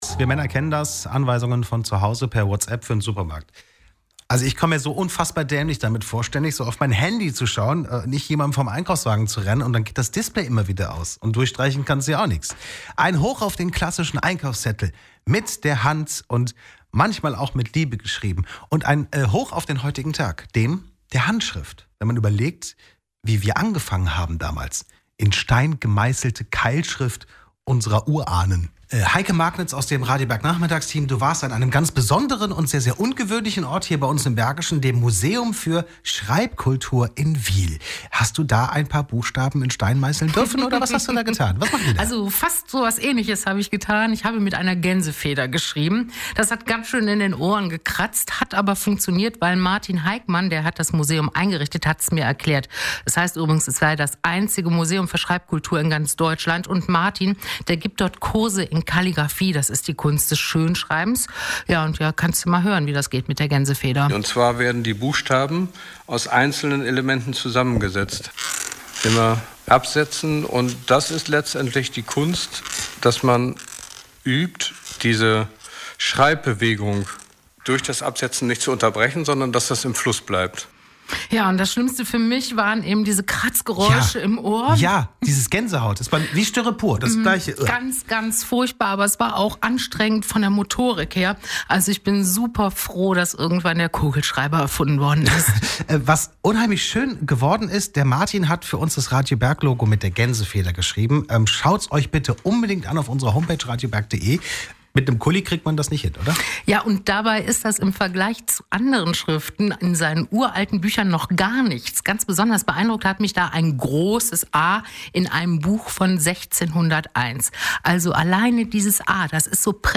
Zu Besuch im Museum für Schreibkultur in Wiehl